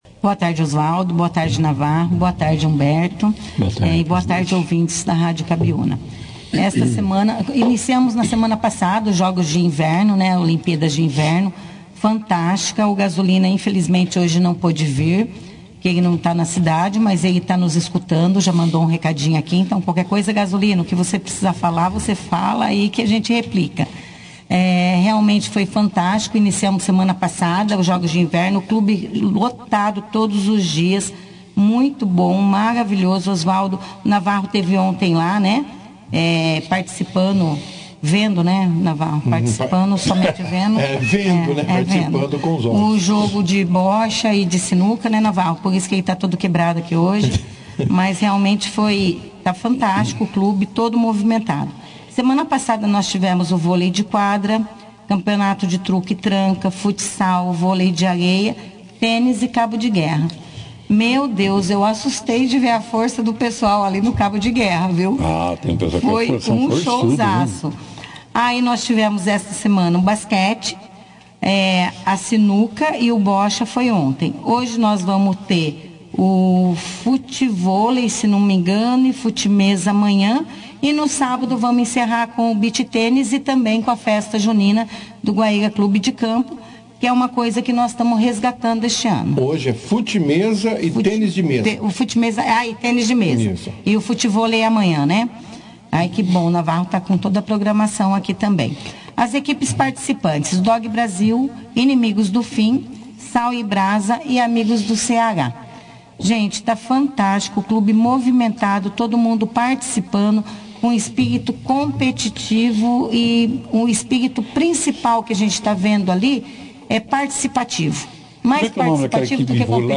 Durante a entrevista, os diretores também destacaram a final das Olimpíadas de Inverno, que ocorrerá no mesmo dia, 01 de julho, e que tem movimentado os associados nas últimas duas semanas.